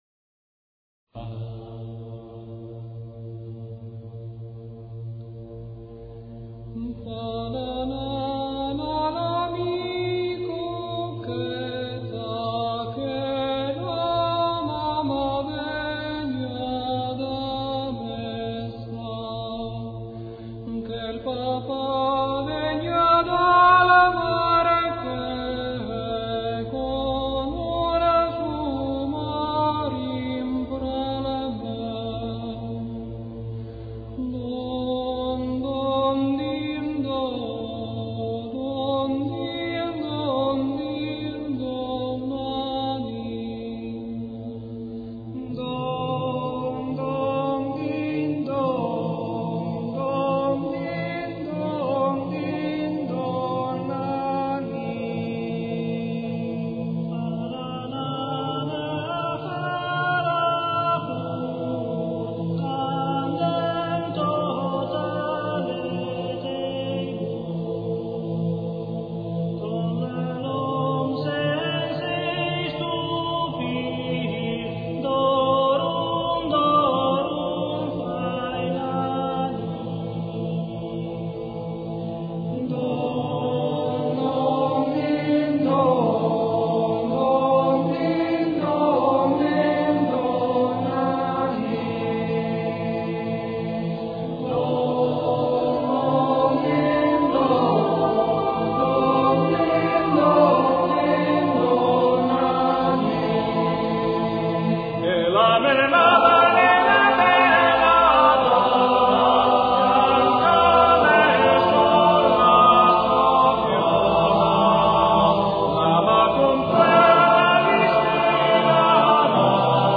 Fa la nâna - Coro Stelutis
[ voci virili ]